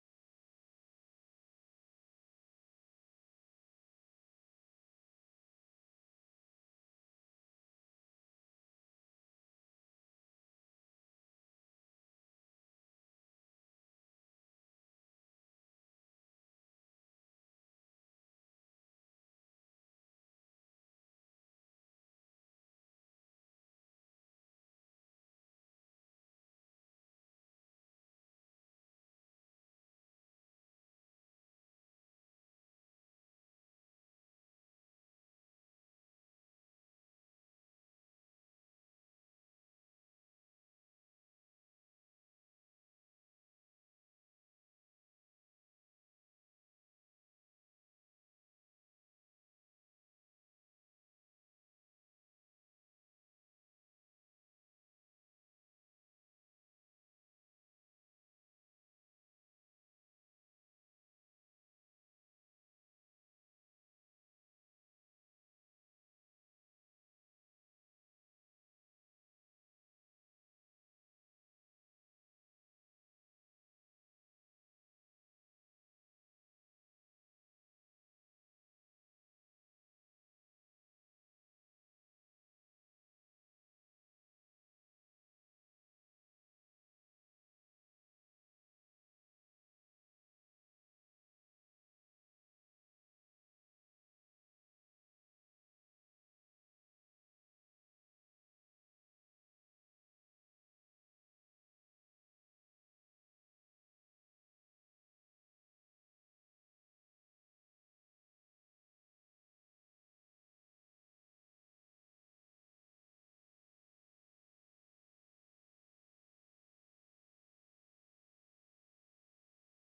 12-17-23 Sunday School Lesson | Buffalo Ridge Baptist Church